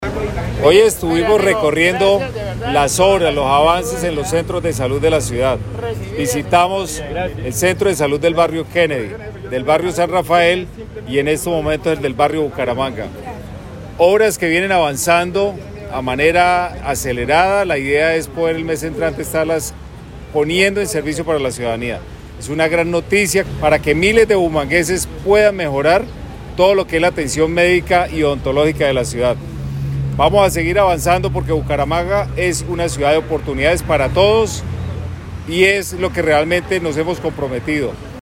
Juan Carlos Cárdenas, alcalde de Bucaramanga
AUDIO-ALCALDE-DE-BUCARAMANGA_CENTROS-DE-SALUD_mezcla.mp3